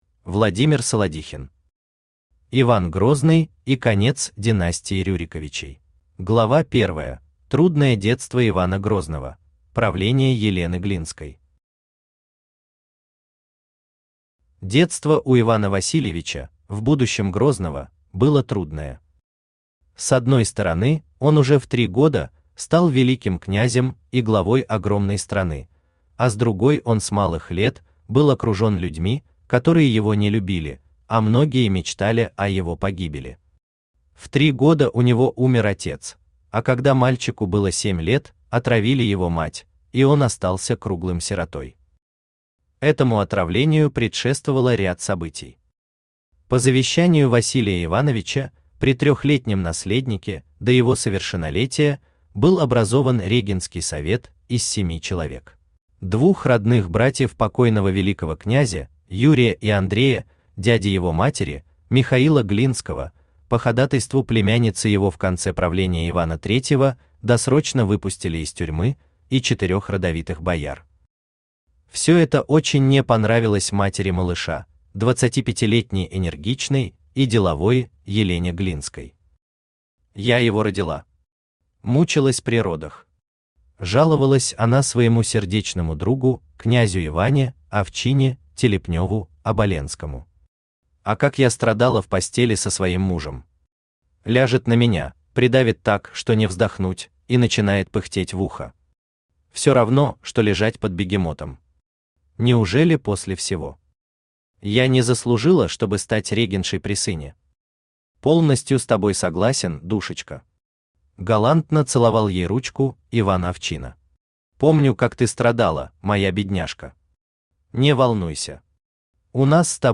Аудиокнига Иван Грозный и конец династии Рюриковичей | Библиотека аудиокниг
Aудиокнига Иван Грозный и конец династии Рюриковичей Автор Владимир Евгеньевич Солодихин Читает аудиокнигу Авточтец ЛитРес.